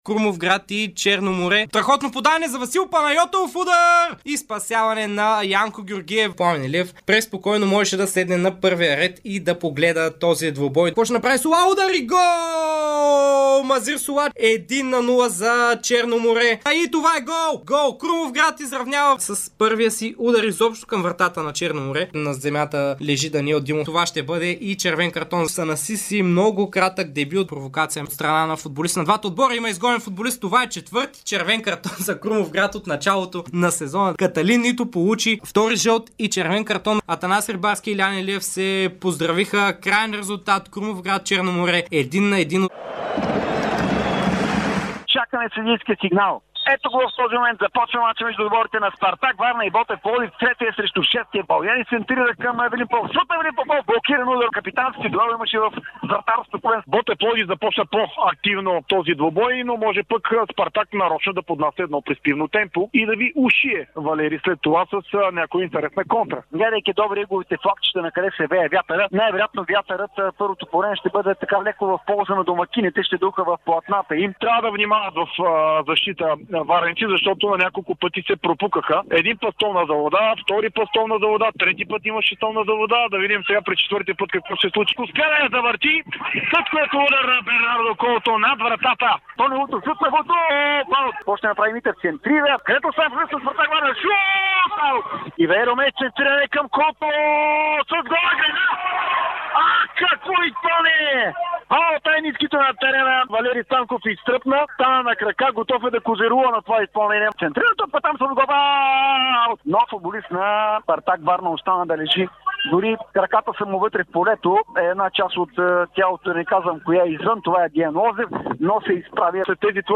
И този понеделник предлагаме емоционалните моменти, на които станахме свидетели в ефира на Дарик от футболните терени през уикенда.
Кой би, кой падна, кой пропусна, кой бе изгонен, кой заслужи и лаф по свой адрес – отговорите от коментаторите на Дарик!